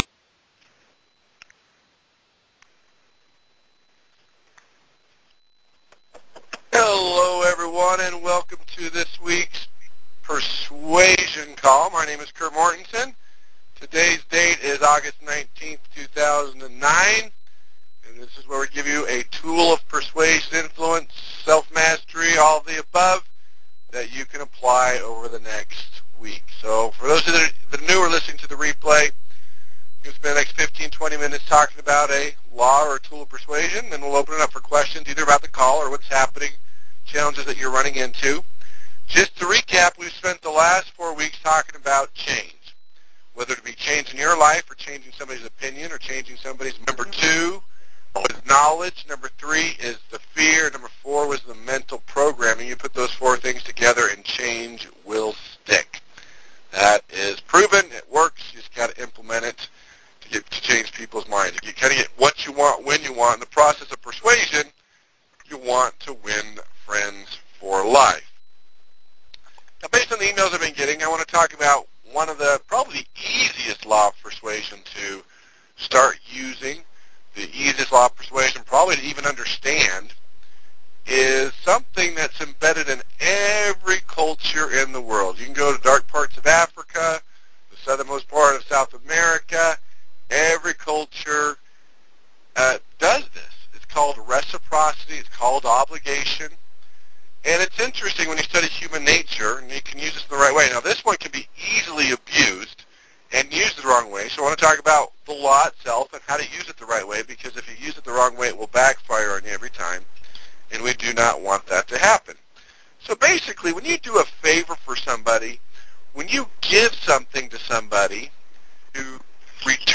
‹ No to yes Optimism › Posted in Conference Calls